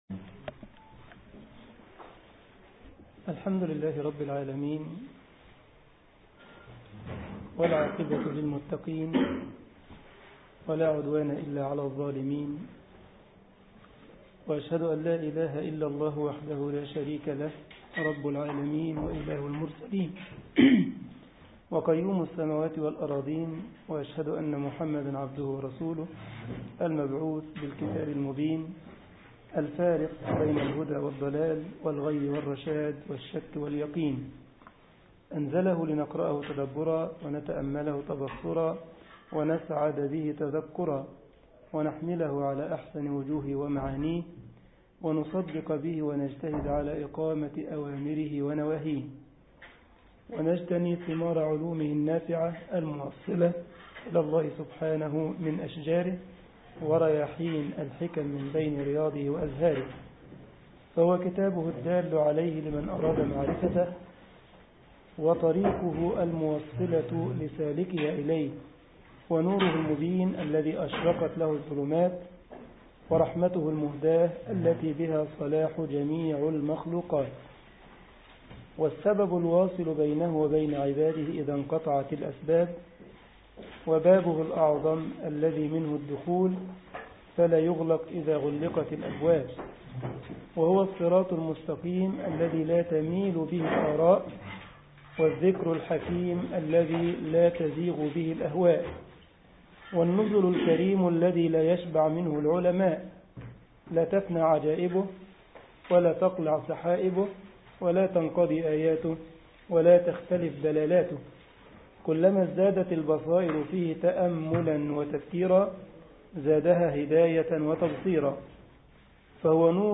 الجمعية الإسلامية بالسارلند ـ ألمانيا درس